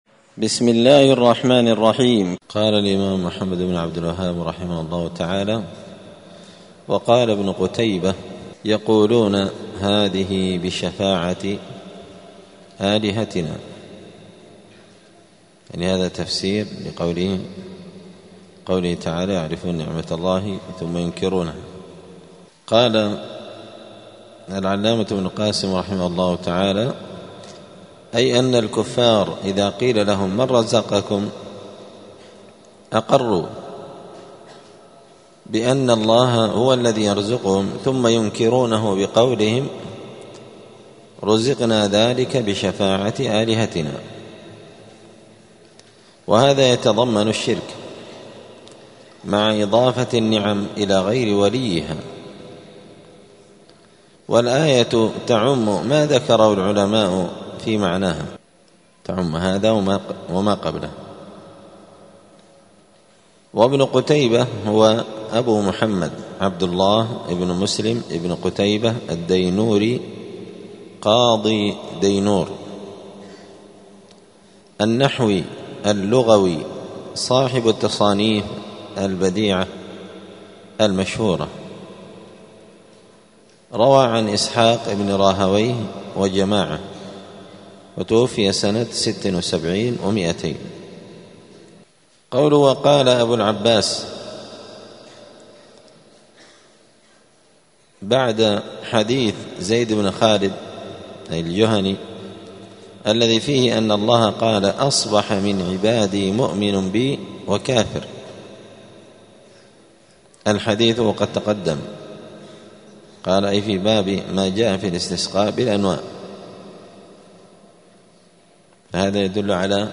دار الحديث السلفية بمسجد الفرقان قشن المهرة اليمن
*الدرس التاسع عشر بعد المائة (119) تابع لباب قول الله تعالى {يعرفون نعمت الله ثم ينكرونها وأكثرهم الكافرون}*